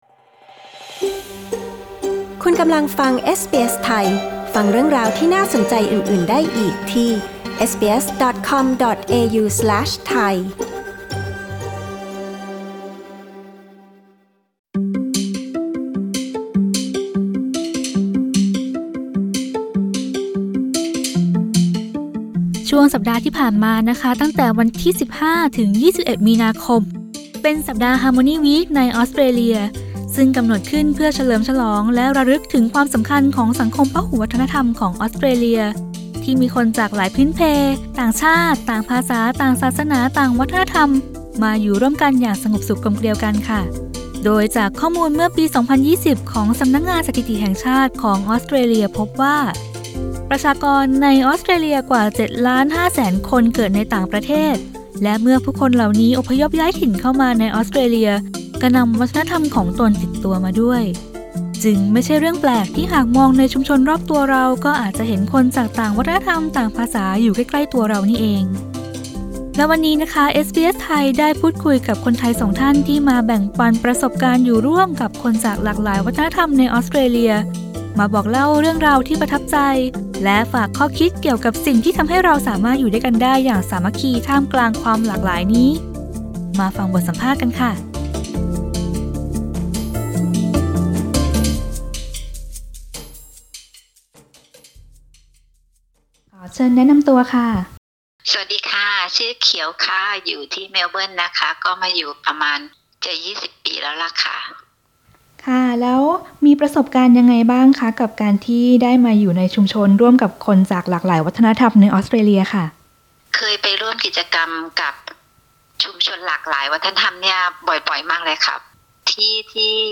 อะไรทำให้ความหลากหลายอยู่ร่วมกันได้โดยสันติ ฟังข้อคิดจากคนไทยสองท่านที่มาแบ่งปันประสบการณ์ร่วมกับผู้คนในชุมชนพหุวัฒนธรรมของออสเตรเลีย